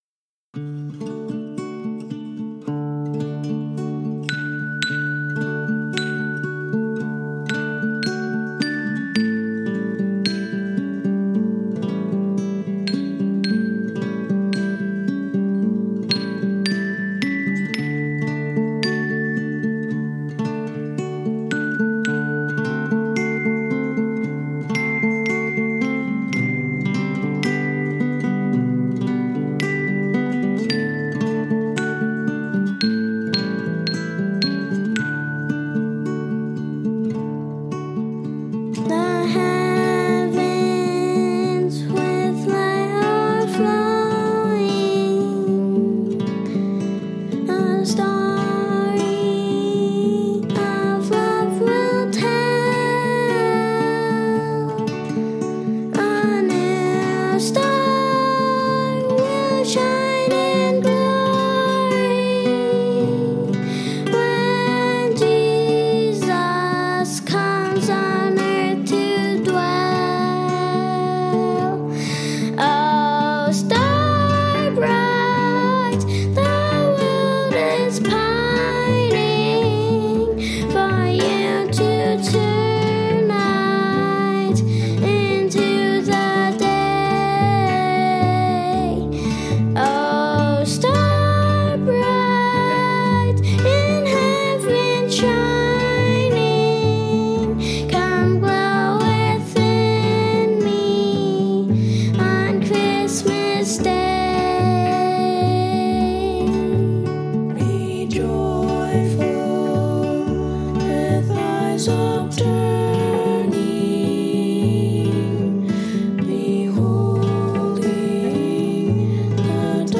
accordion
bells